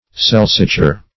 Search Result for " celsiture" : The Collaborative International Dictionary of English v.0.48: Celsiture \Cel"si*ture\, n. [L. celstudo, from celsus high: cf. celsitude.]